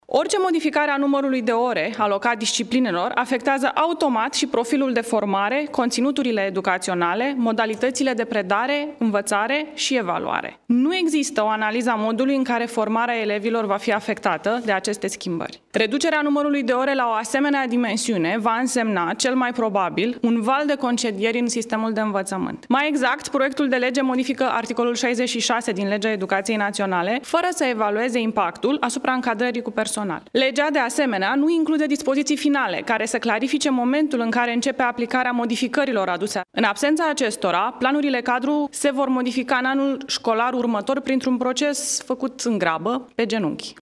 Demersul denotă lipsa unei strategii şi a unei viziuni pe termen mediu şi lung în acest sector esenţial pentru viitorul României’, a mai afirmat Ligia Deca, într-o conferinţă de presă susţinută la Palatul Cotroceni: